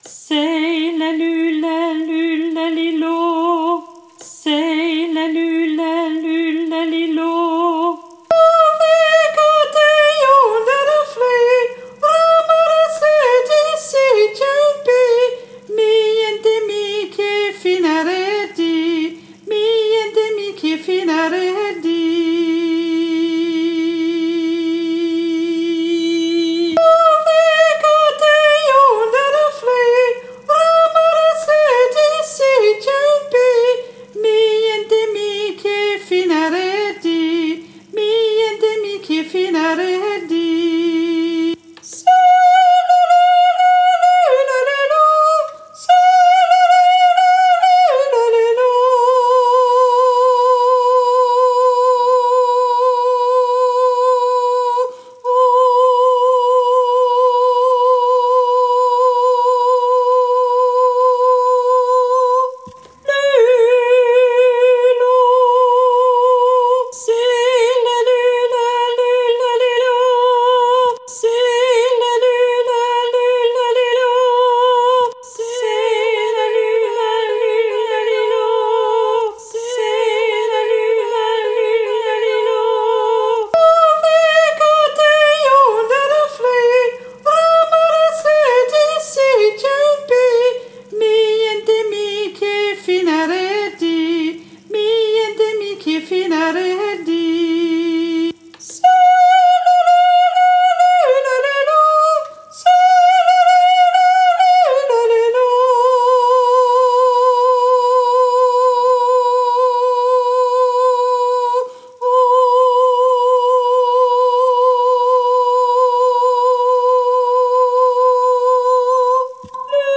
Tenor 1 Tenor 2